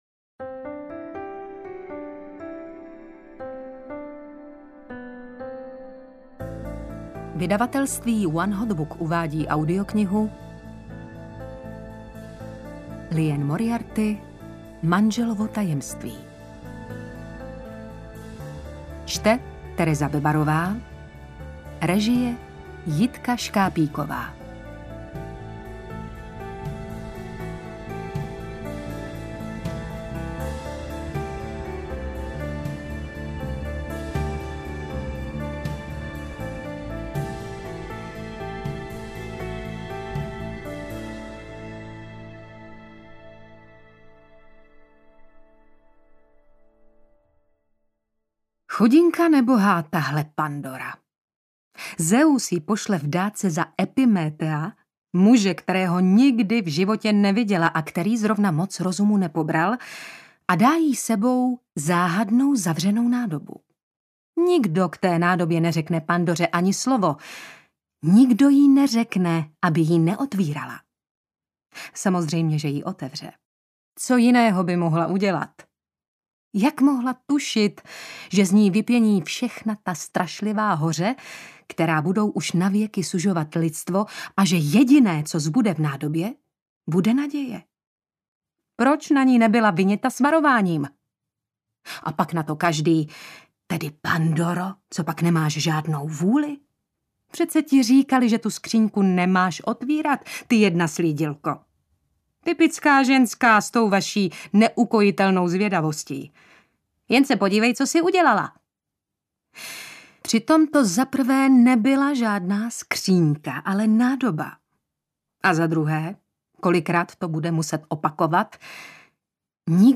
Interpret:  Tereza Bebarová
AudioKniha ke stažení, 59 x mp3, délka 12 hod. 57 min., velikost 691,0 MB, česky